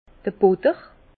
Bas Rhin d'r Butter
Prononciation 67 Herrlisheim